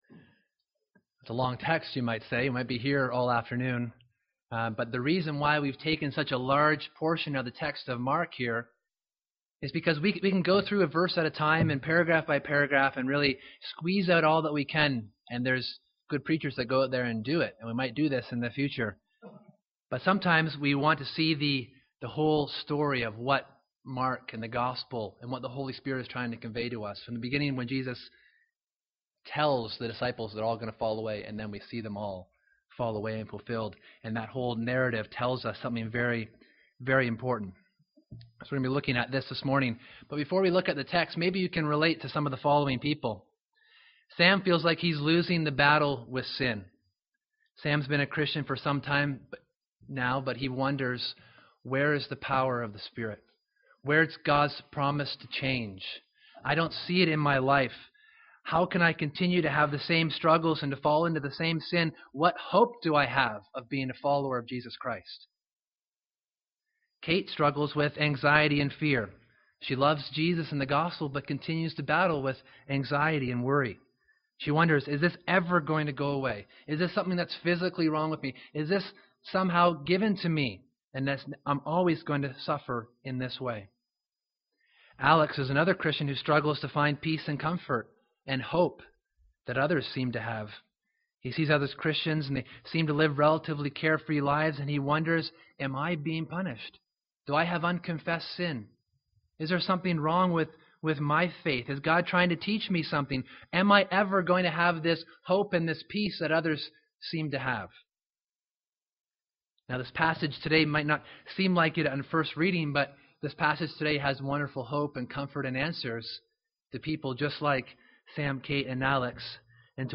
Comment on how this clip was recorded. October 12, 2014 ( Sunday AM ) Bible Text